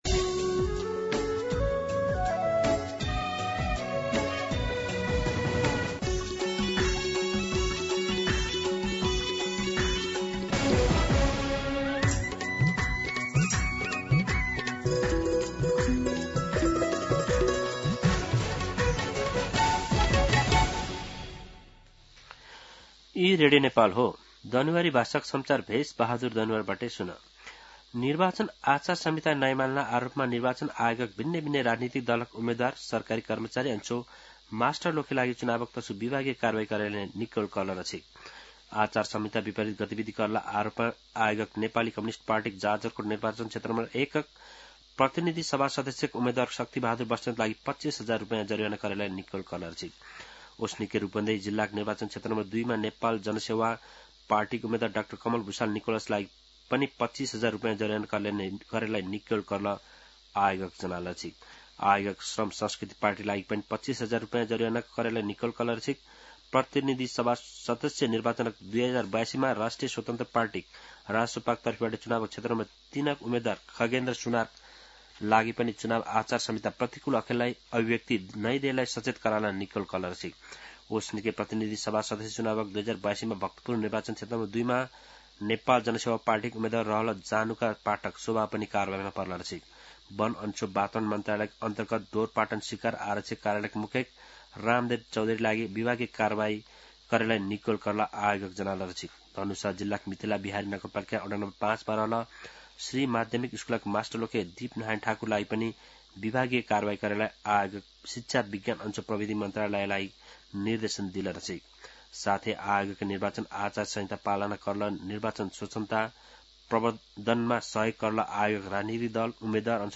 दनुवार भाषामा समाचार : १५ फागुन , २०८२